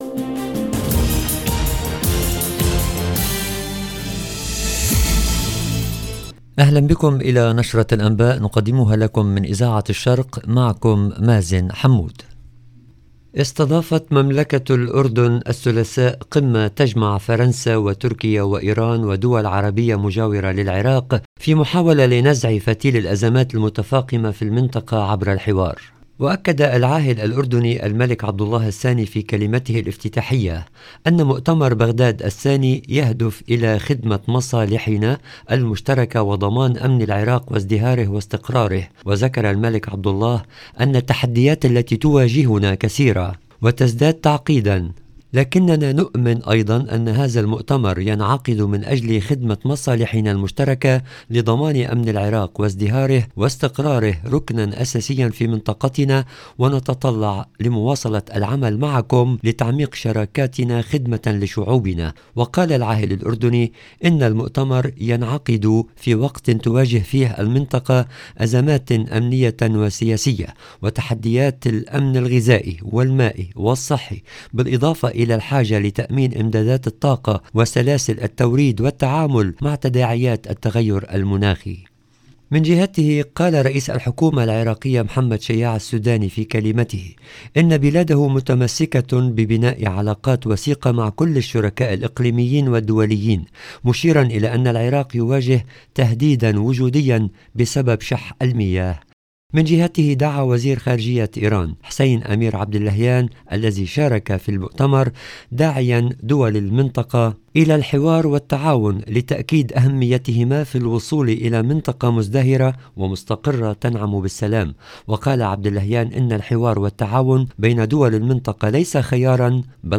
LE JOURNAL DU SOIR EN LANGUE ARABE DU 20/12/22